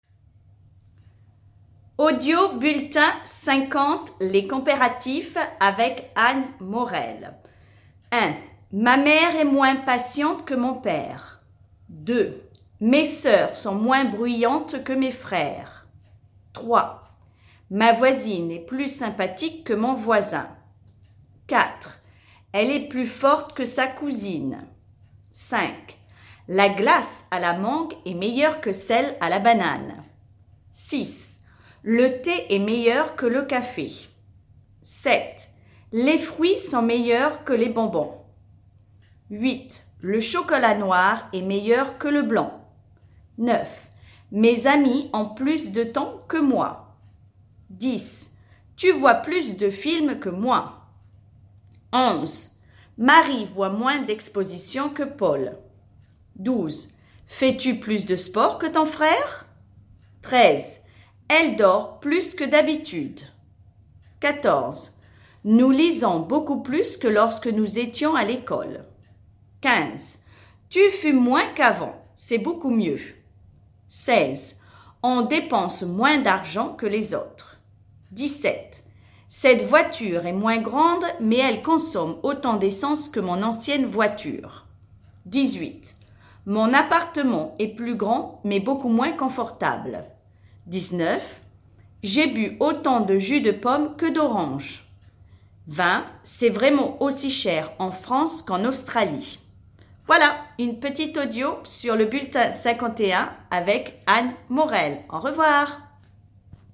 Le Bulletin #50 est une belle dictée dans laquelle vous allez trouver des phrases d’exemples sur les comparatifs.